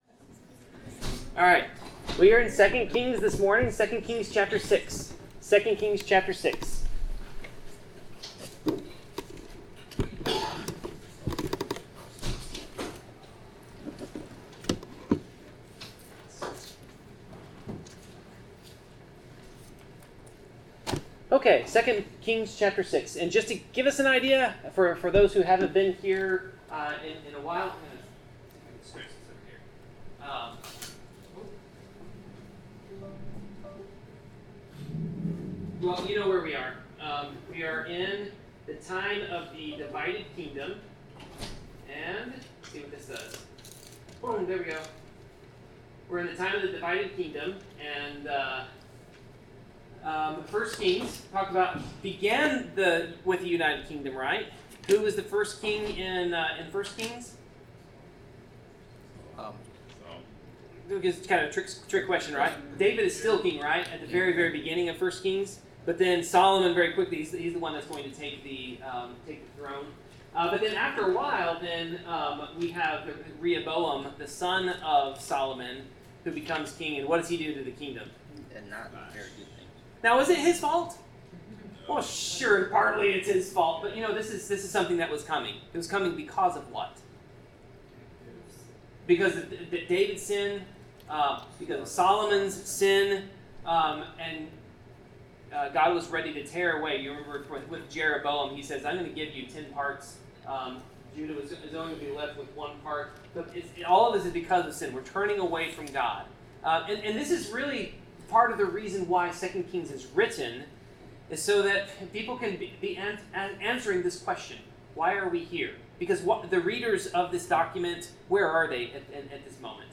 Bible class: 2 Kings 6-7
Passage: 2 Kings 6-7 Service Type: Bible Class